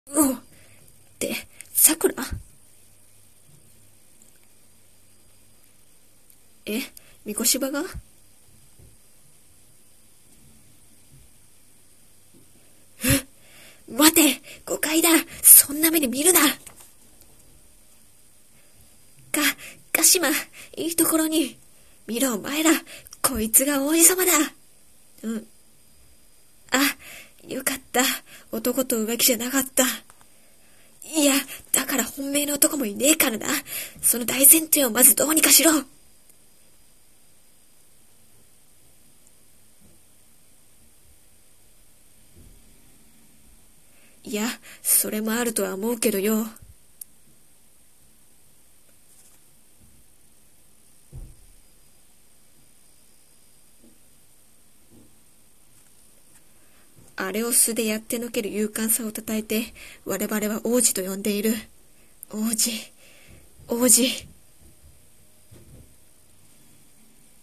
【アフレココラボ募集】月刊少女野崎くん１巻!!修羅場～王子様